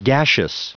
Prononciation du mot gaseous en anglais (fichier audio)
Prononciation du mot : gaseous
gaseous.wav